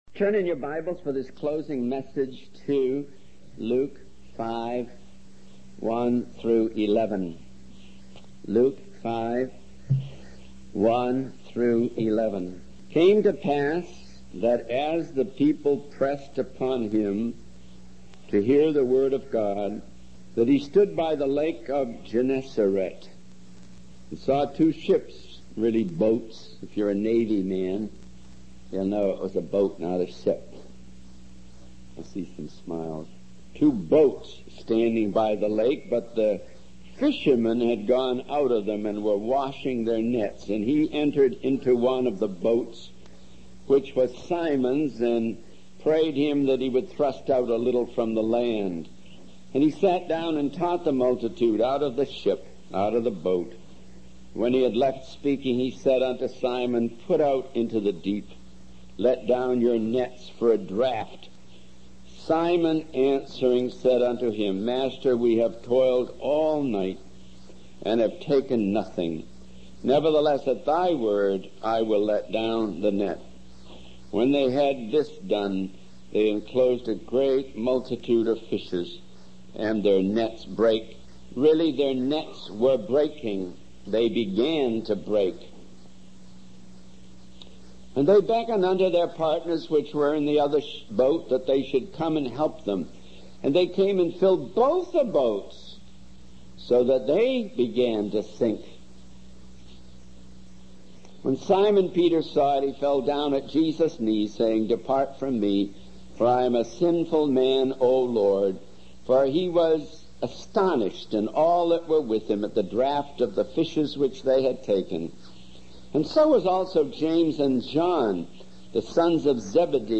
In this sermon, the speaker emphasizes the importance of a lifetime of preparation for preaching the word of God. He uses the example of the disciples in the Book of Acts who had spent their lives with Jesus before they were able to effectively minister. The speaker encourages the audience to 'launch out into the deep' and step out of their comfort zones in order to fulfill their calling.